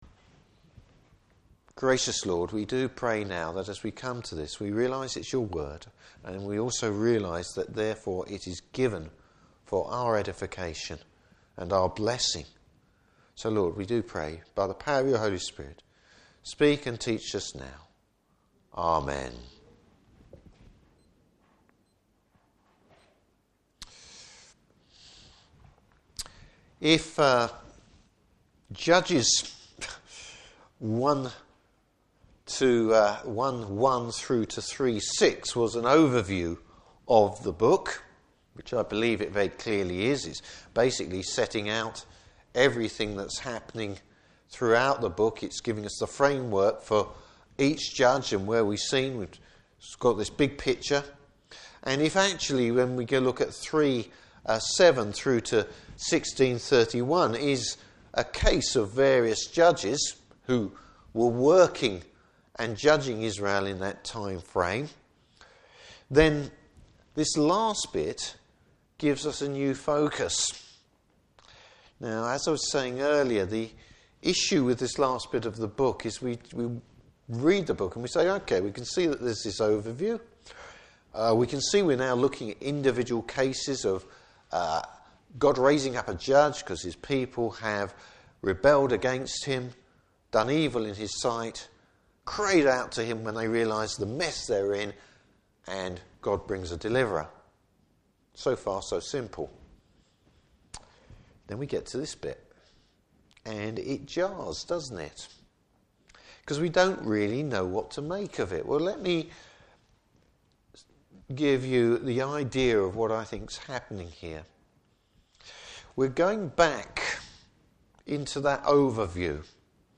Service Type: Evening Service Bible Text: Judges 17:1-18:31.